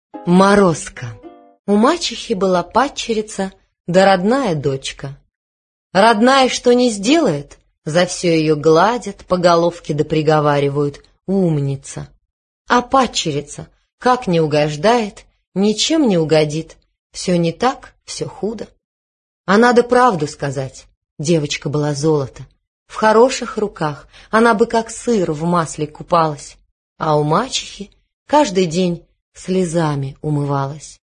Аудиокнига Морозко